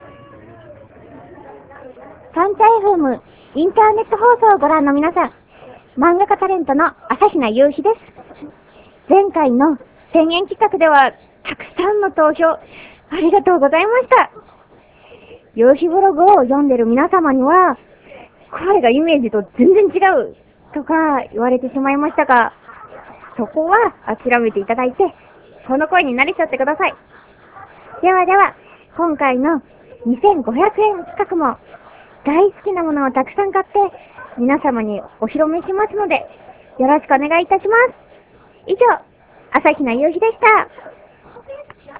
リポート等の音声はスタジオ録音ではなく現地録りになりますので他の人の声などの雑音が入っていたりしますがご了承願います。